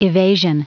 Prononciation du mot evasion en anglais (fichier audio)
Prononciation du mot : evasion